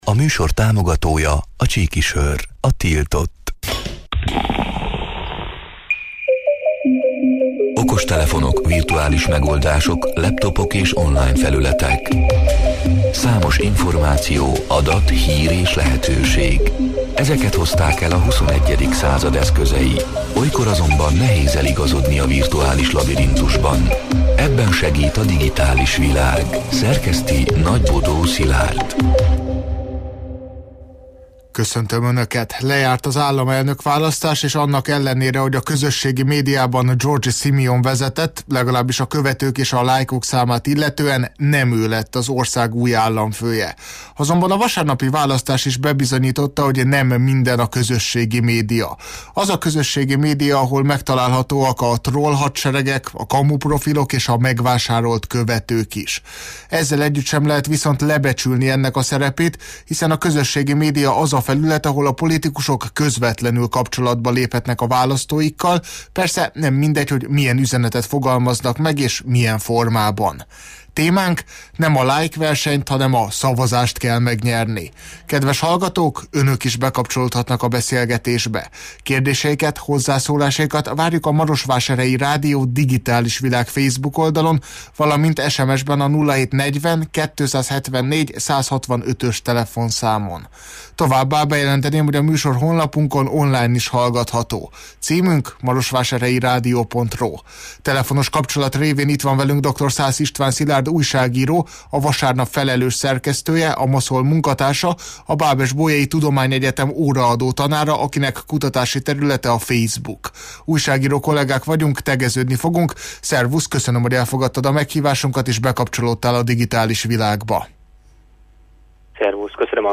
A Marosvásárhelyi Rádió Digitális Világ (elhangzott: 2025. május 20-án, kedden este nyolc órától élőben) c. műsorának hanganyga: